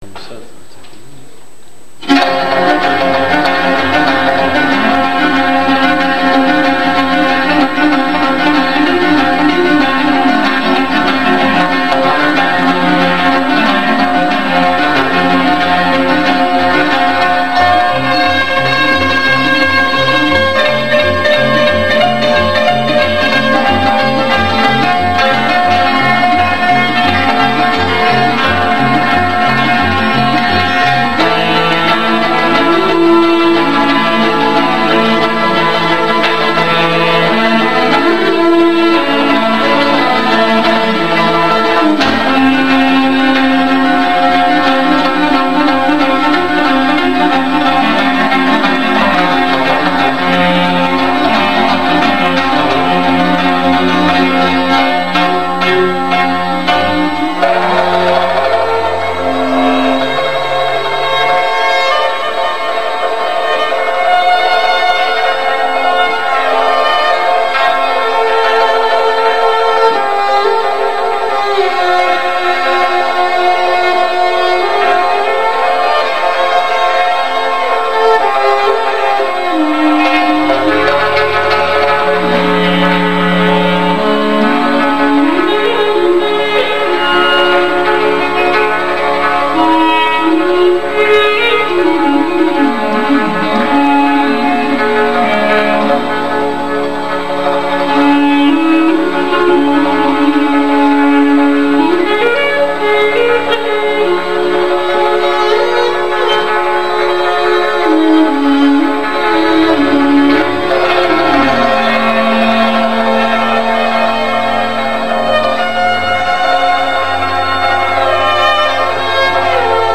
日没のあとに始まったコンサートは5人構成の立派なもので、軽業も含めてなかなかのものでした。
民族楽器で奏でられるテンポの良い曲です。